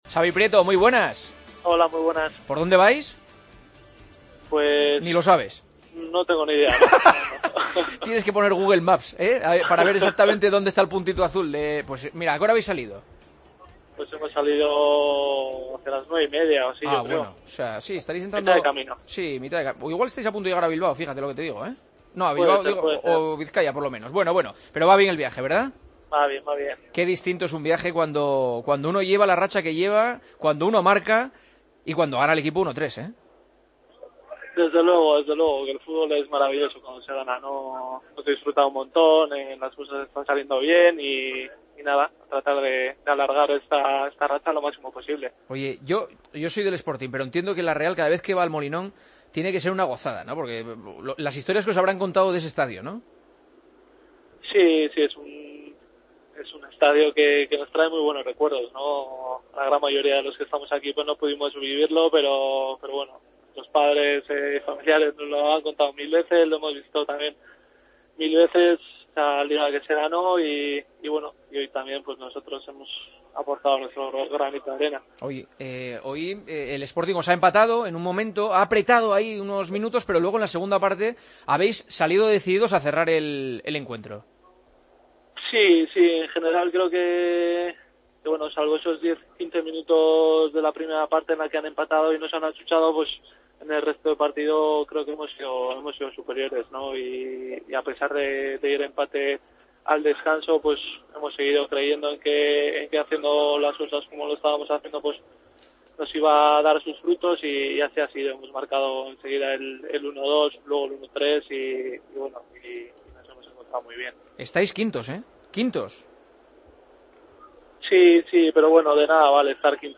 Conectamos con el autobús que le lleva a la Real Sociedad de vuelta a casa con la victoria de Gijón en el bolsillo: "No vale de nada que estemos quintos a estas alturas, queda mucho que mejorar, aunque queremos prolongar este momento lo máximo posible.
Con Paco González, Manolo Lama y Juanma Castaño